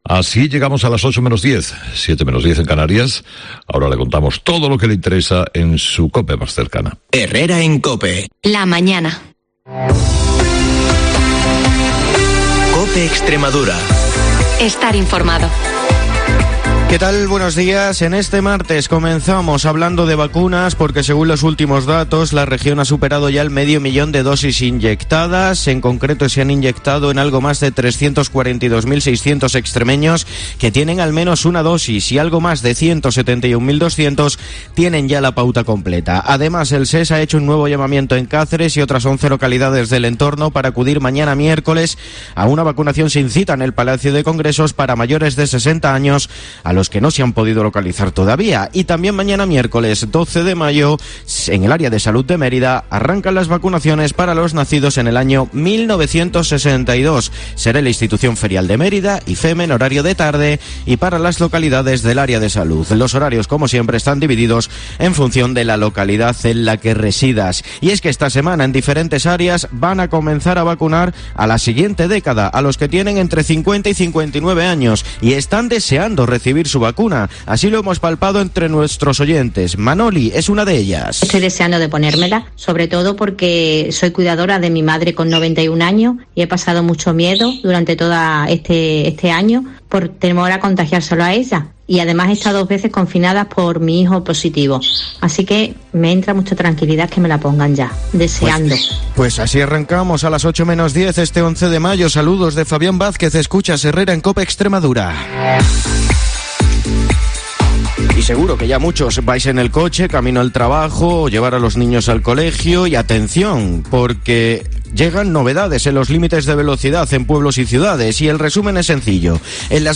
el informativo más escuchado del día de la radio en Extremadura